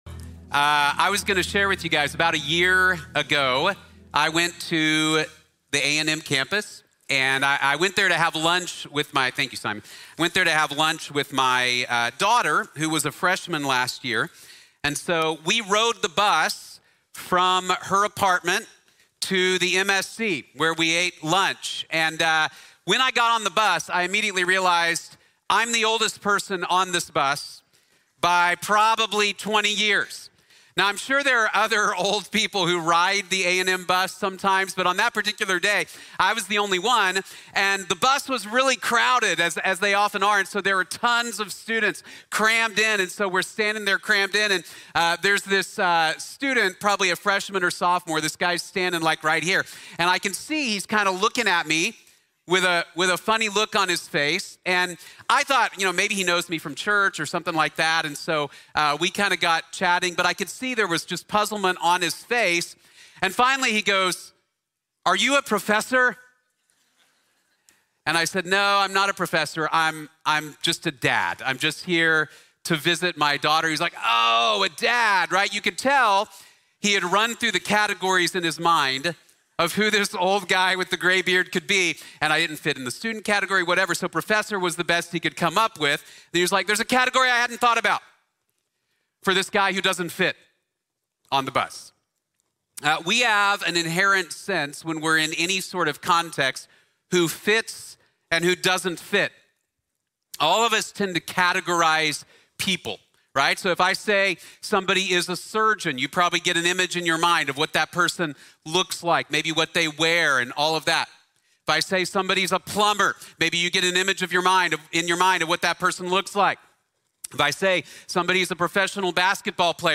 Anyone, Anyway, Anywhere | Sermon | Grace Bible Church